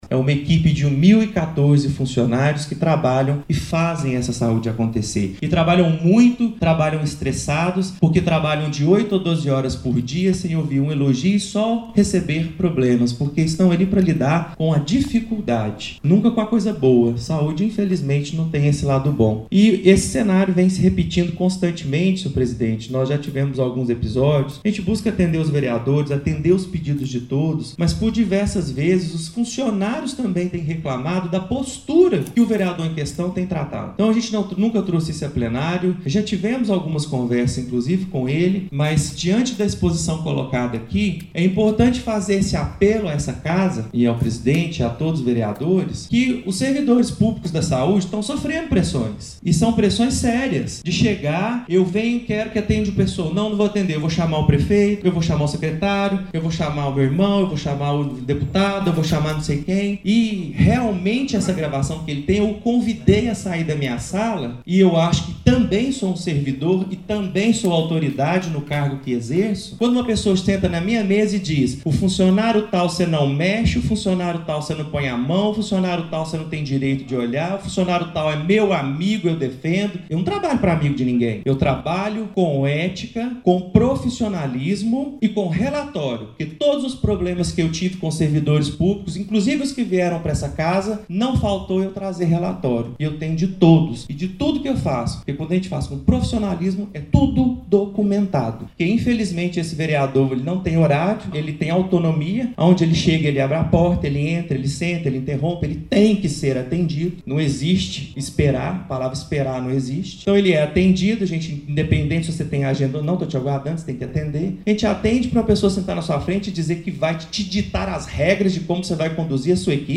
A pedido do vereador Márcio Lara (PTC), o plenário aprovou o uso da tribuna pelo secretário Paulo Duarte como direito de resposta as questões levantadas pelo vereador Antônio Carlos dos Santos.